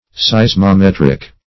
Meaning of seismometric. seismometric synonyms, pronunciation, spelling and more from Free Dictionary.